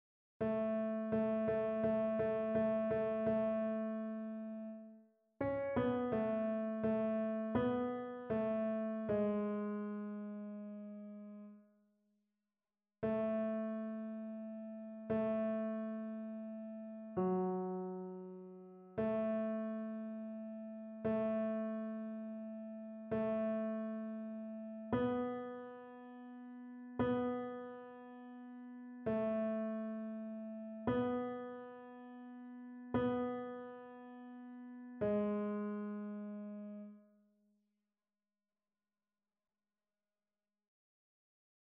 TénorBasse
annee-b-temps-ordinaire-14e-dimanche-psaume-122-tenor.mp3